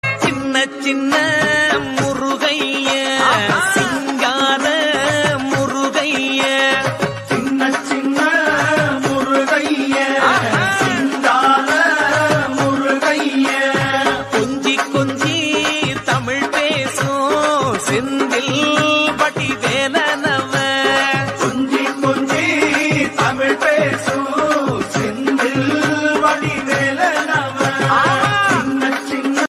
devotional ringtone